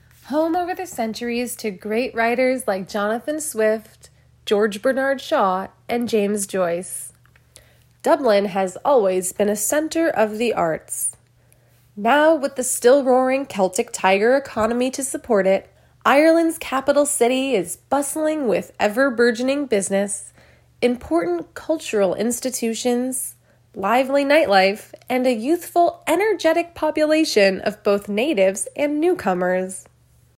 ROOM NOISE: This
The reason I chose this file was because you can hear the obvious room tone in the background. It has a low frequency hum and hiss.